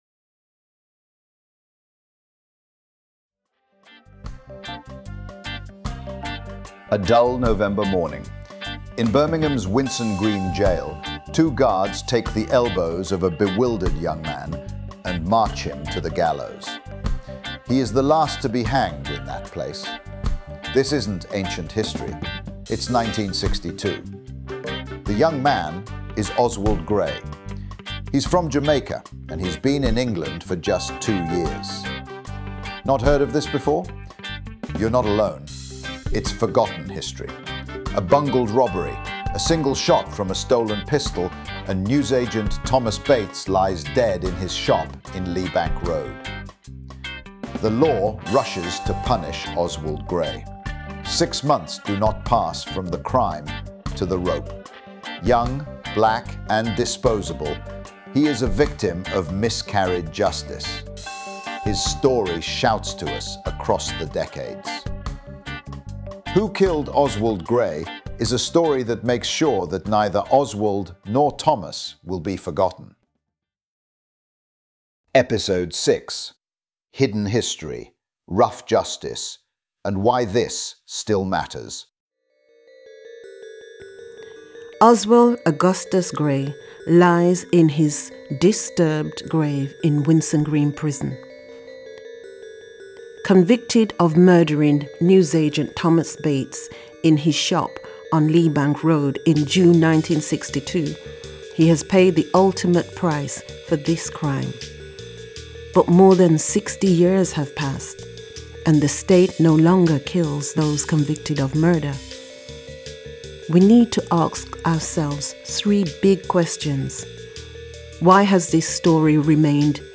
Birmingham people of Jamaican heritage talk about this – and we look really closely at a final summary of why this was, indeed, a potential miscarriage of justice that still requires investigation.